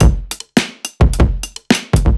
Index of /musicradar/off-the-grid-samples/110bpm
OTG_Kit9_Wonk_110a.wav